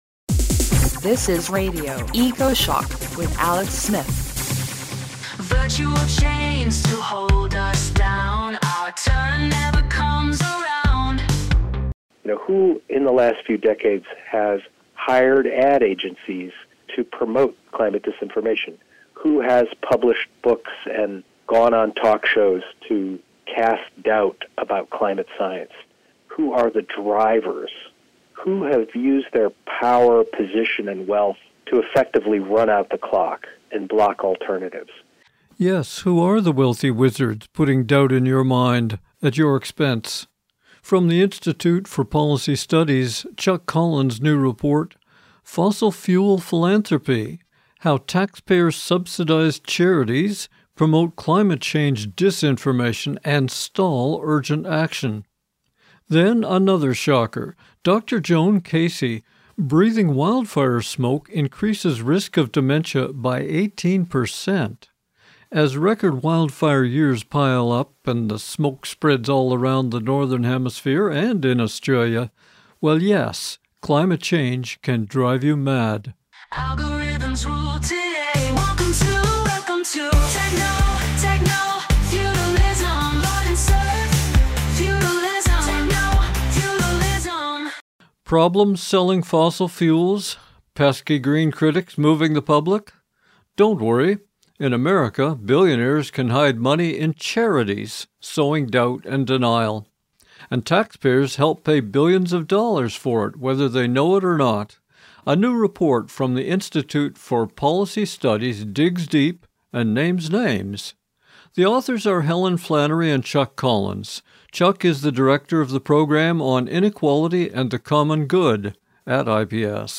Radio Ecoshock broadcast affiliate version (58 minutes, 54MB, 128Kbps, stereo mp3) of our Weekly 1 hour program featuring the latest science, authors, issues - from climate change, oceans, forests, pollution, Peak Oil, the economy, and peace.